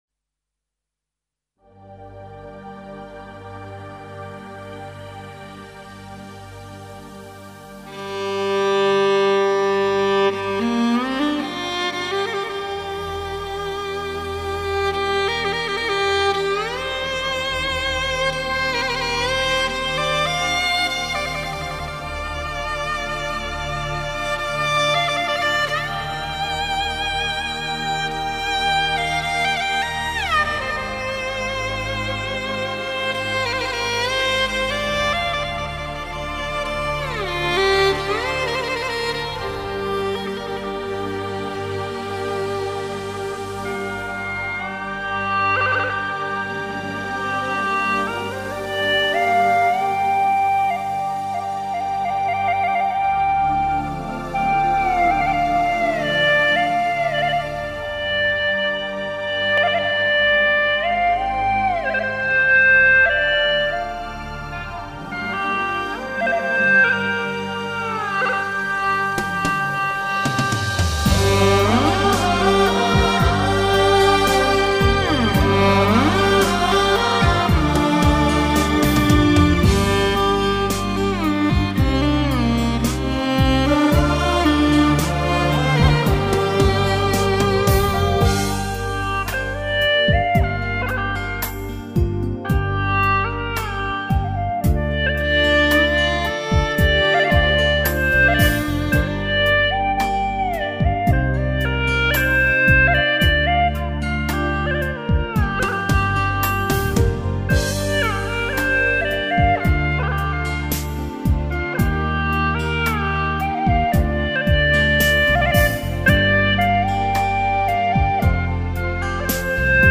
调式 : 降B 曲类 : 流行
bB调葫芦丝演奏。在原曲上适当做了点改编，加入了引子和吐奏，让通俗歌曲变得像葫芦丝独奏曲了。